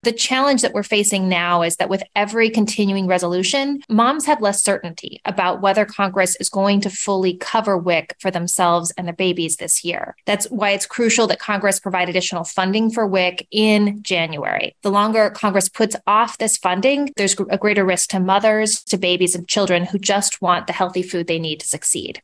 USDA Deputy Secretary Xochitl Torres Small is asking Congress for full funding in January.
Audio with Neera Tanden, Director of the Domestic Policy Council, and Xochitl (so-CHEEL) Torres Small, Deputy Secretary of the USDA